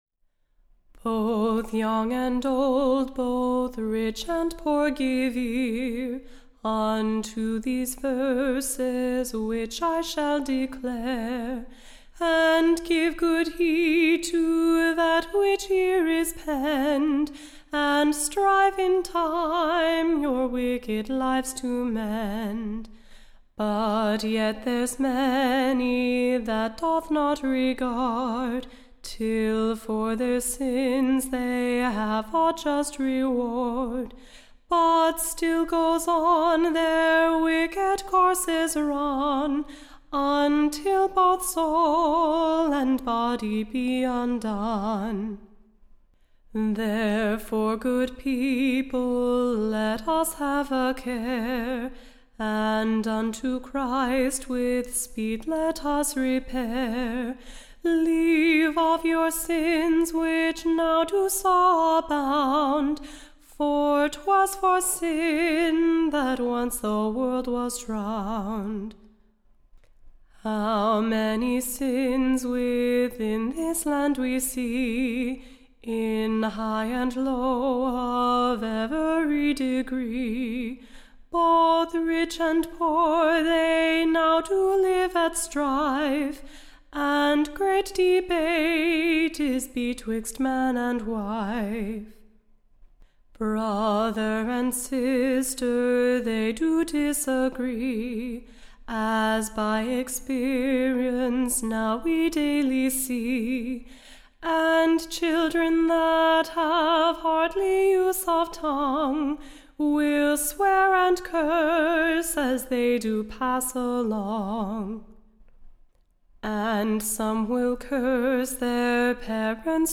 Recording Information Ballad Title A Letter for a Christian Family.
Standard Tune Title Fortune my Foe Media Listen 00 : 00 | 7 : 57 Download P3.102v.mp3 (Right click, Save As)